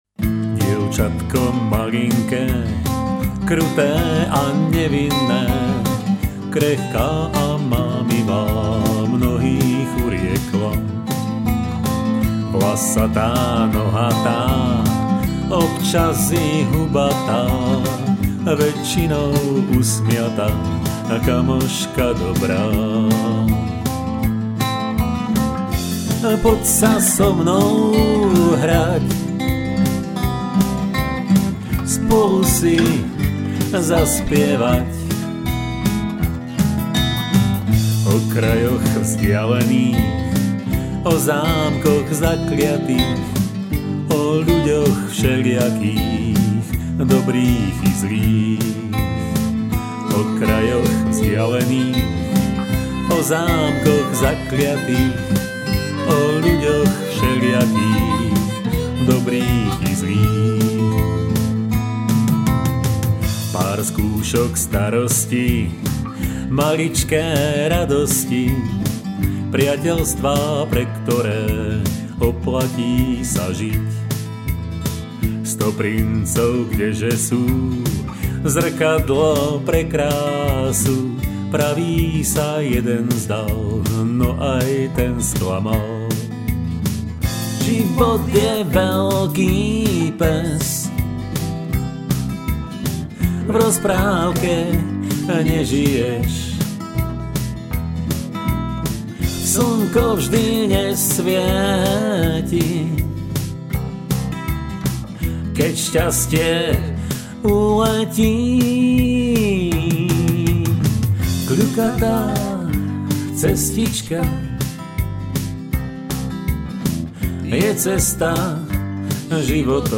12 st. gitara, djembe, fúkacia harmonika, koncovka